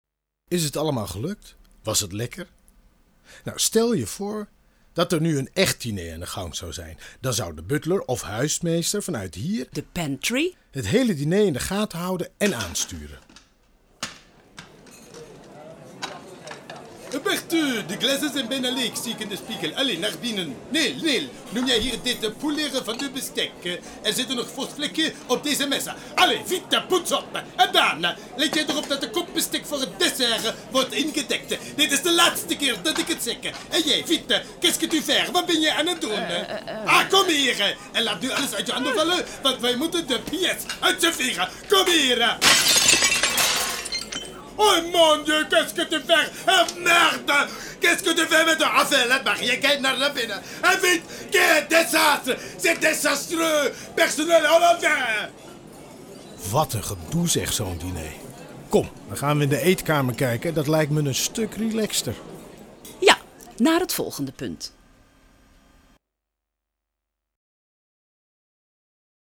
Hoorspel over Kasteel Sterkenburg te Driebergen.
diner_op_kasteel_sterkenburg.mp3